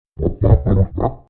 Boss_COG_VO_question.mp3